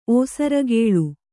♪ ōsaragēḷu